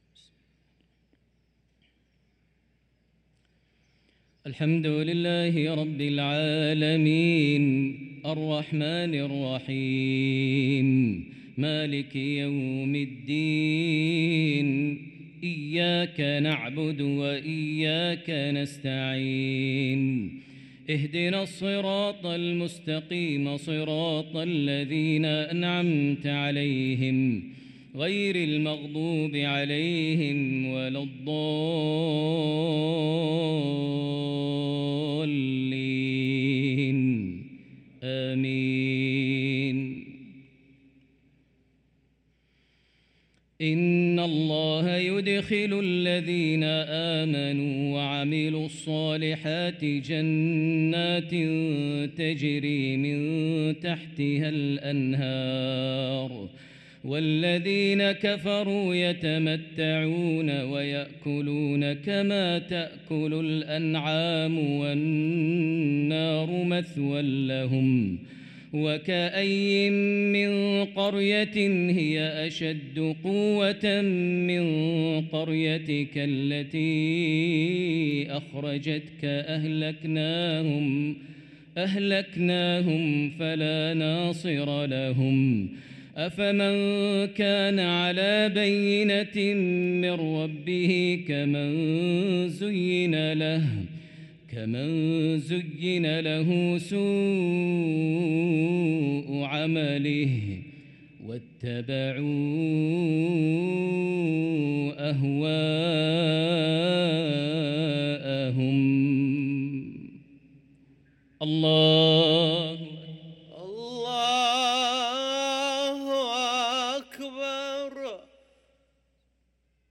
صلاة المغرب للقارئ ماهر المعيقلي 22 صفر 1445 هـ
تِلَاوَات الْحَرَمَيْن .